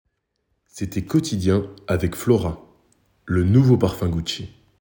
Version 2 posé